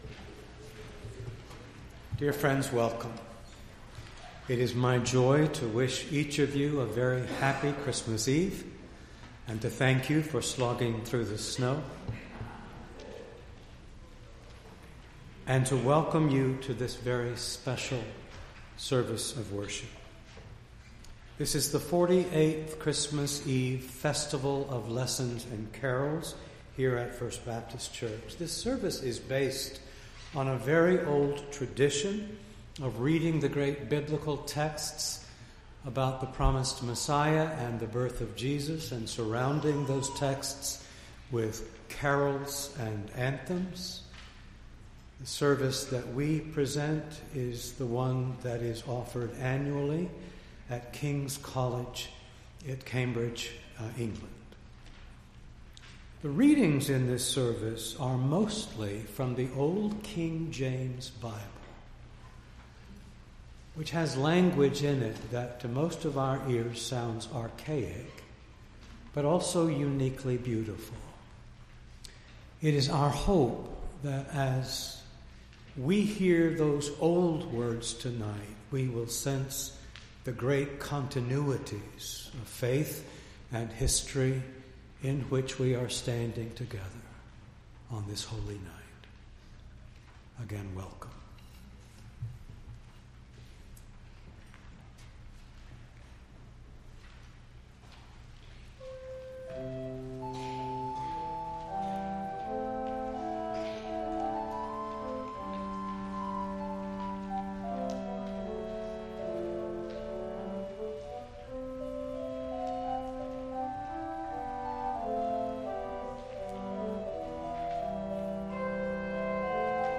December 24, 2017 – A Festival of Lessons & Carols | First Baptist Church of Ann Arbor
Entire December 24th Service
Lessons-Carols-2017.mp3